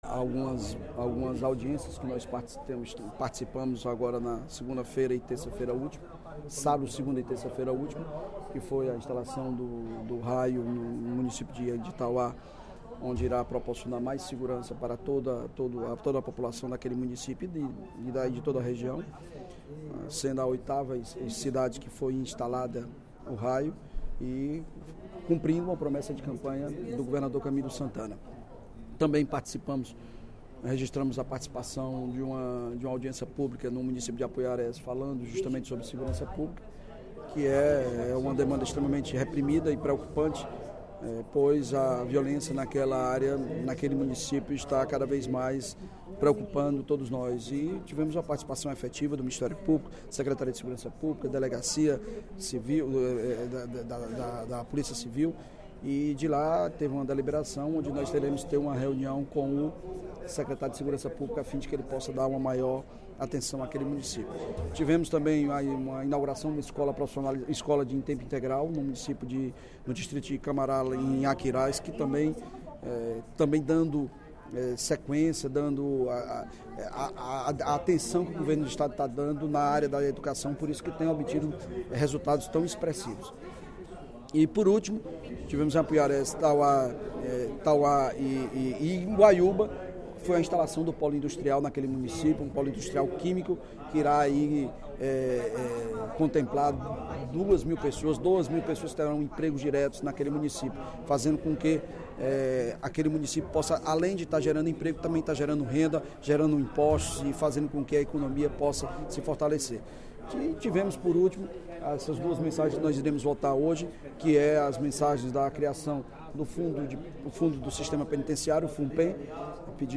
O líder do Governo na Assembleia, deputado Evandro Leitão (PDT), destacou, durante o primeiro expediente da sessão plenária desta quinta-feira (23/02), a agenda de atividades do chefe do Executivo nos últimos dias.